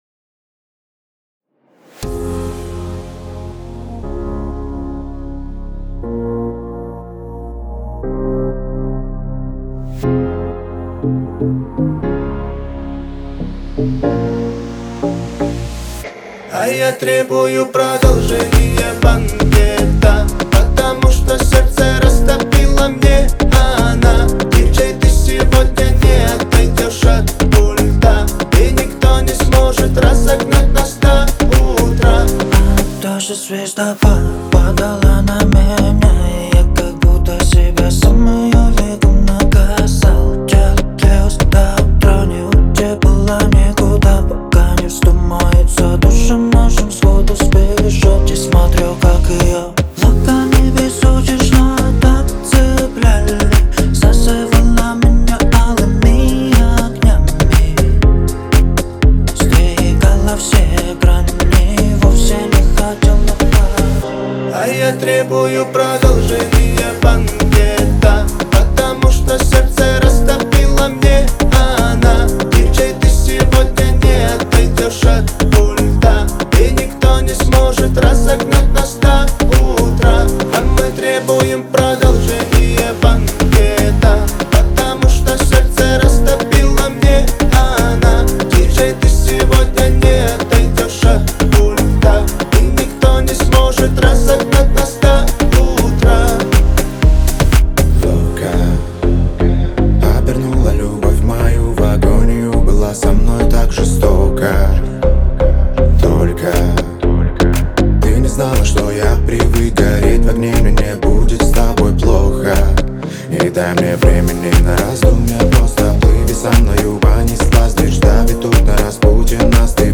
это яркая композиция в жанре хип-хоп